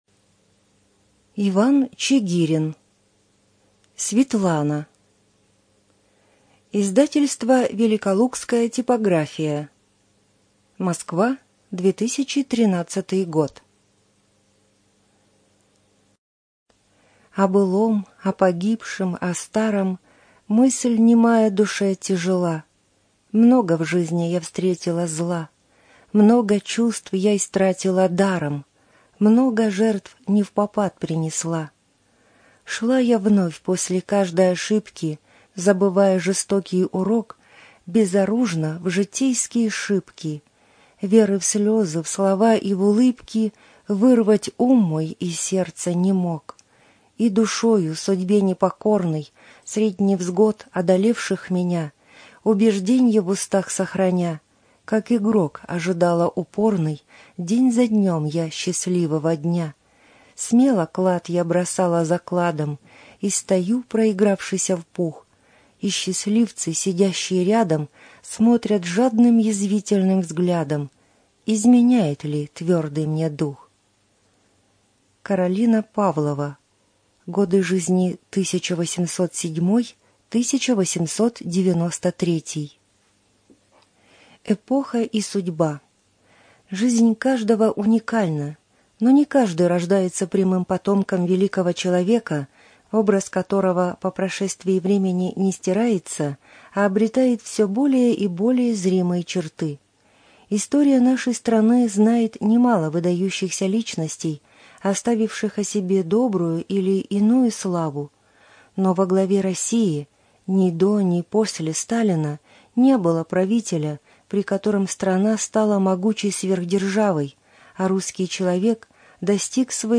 Студия звукозаписиБелгородская областная библиотека для слепых имени Василия Яковлевича Ерошенко